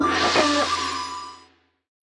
音效
Media:RA_Dragon_Chicken_Dep_006.wav 部署音效 dep 局内选择该超级单位的音效